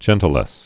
(jĕntə-lĕs)